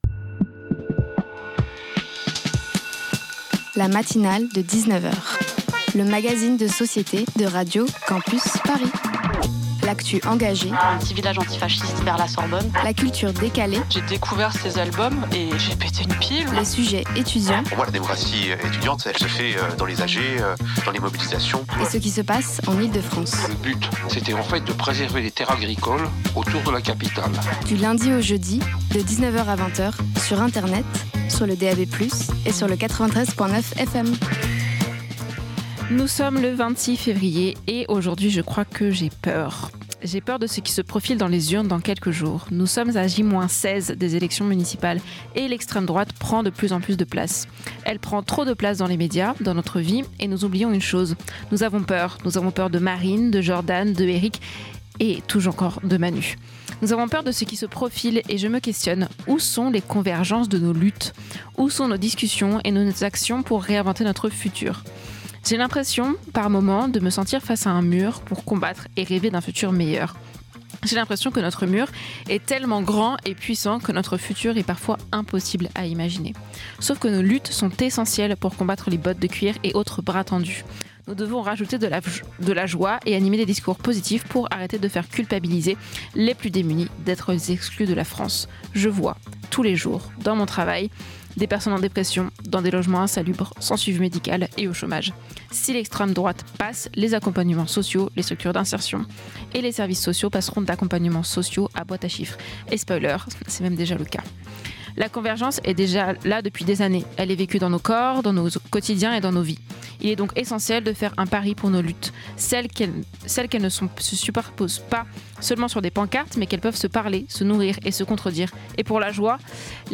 Radio Campus Paris est la radio associative et locale des étudiants et des jeunes franciliens.